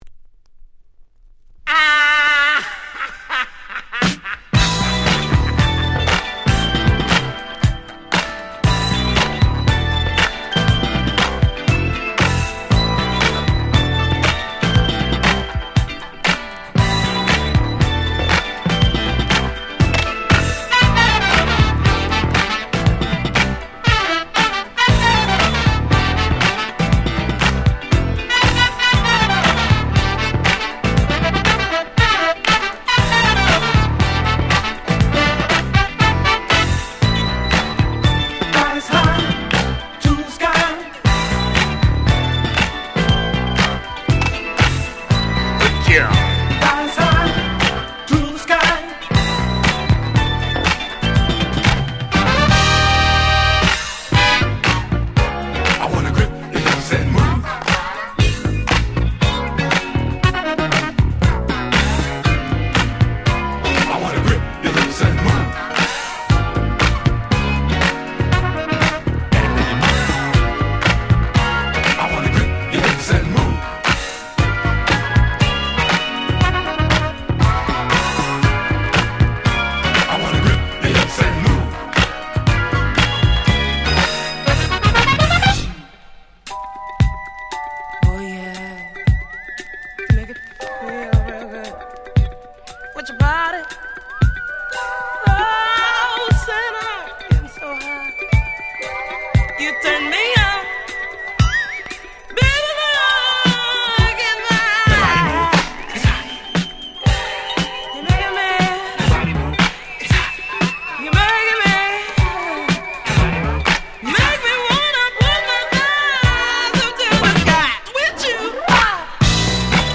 80' Super Hit Disco / Dance Classics !!